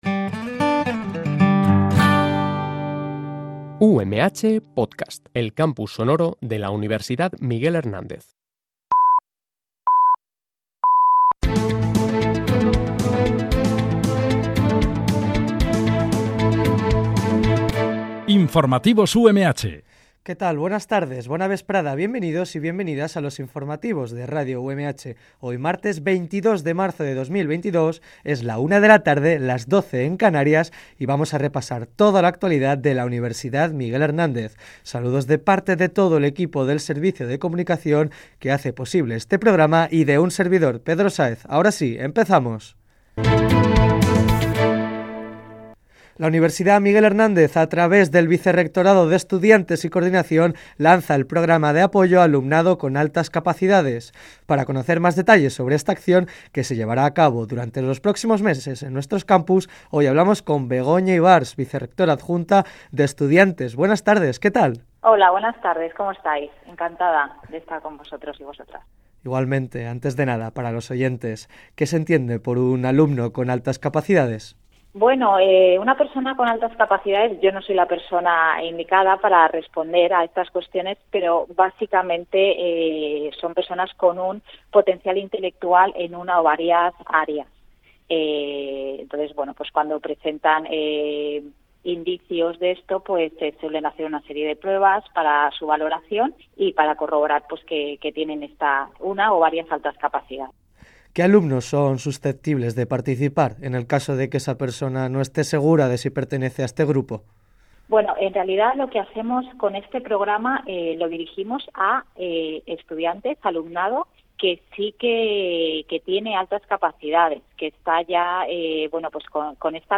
Por último, esta semana se abre el plazo para la inscripción de las actividades dirigidas deportivas en el Campus de Elche y Sant Joan de la UMH. Este programa de noticias se emite de lunes a viernes, de 13.00 a 13.10 h